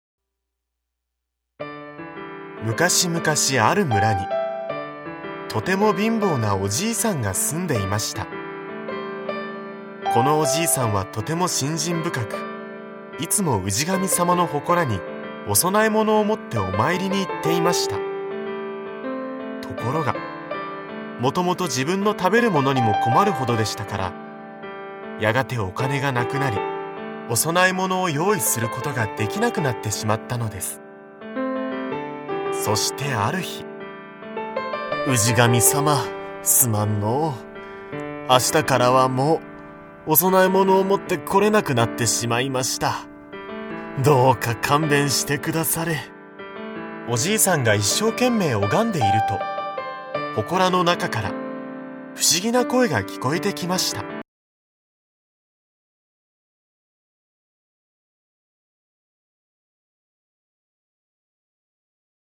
[オーディオブック] ききみみずきん 世界の童話シリーズその44
大人も子どもも一緒になって、多彩なキャストと、楽しい音楽でお楽しみ下さい。
大人も子供も楽しめる童話オーディオブックを、多彩なキャストとBGMでお届けします。